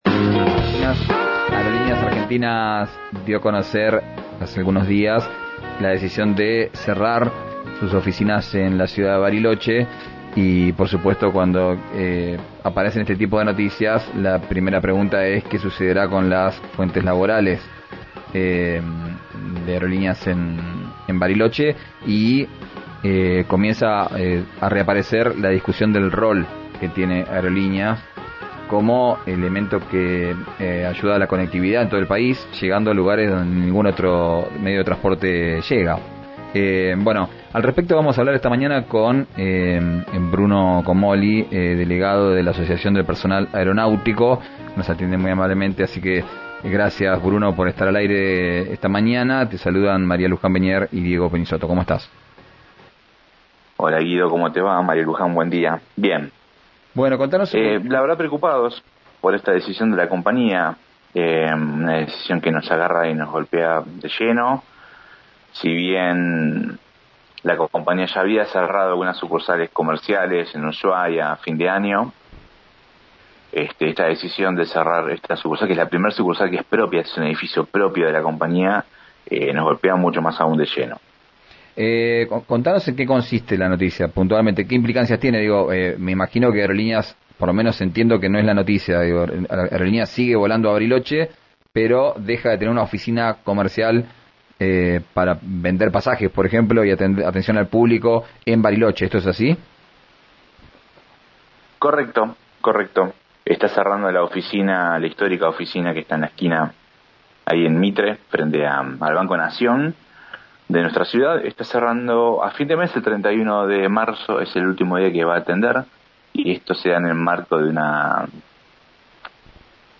en una entrevista con RIO NEGRO RADIO comunicó que además cerrarán las oficinas de «Posadas